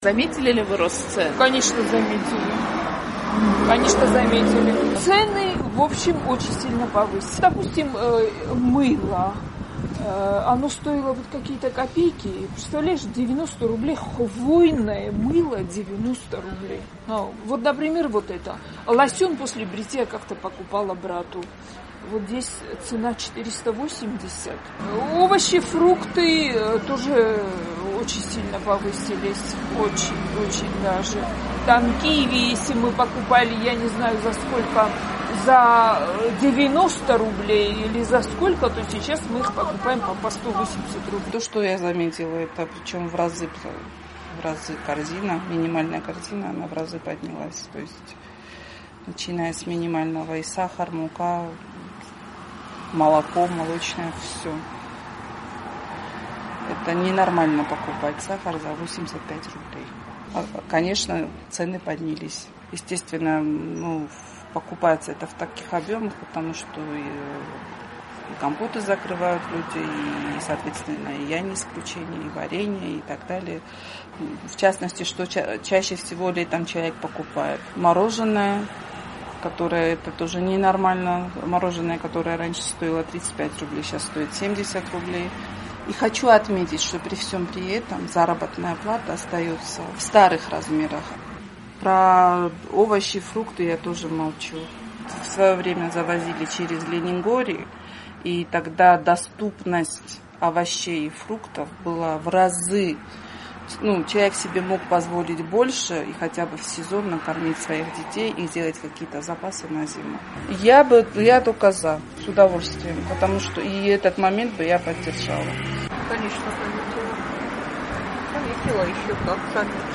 В Южной Осетии продолжается рост цен. «Эхо Кавказа» спросило у жителей Цхинвала, на что они выросли в первую очередь.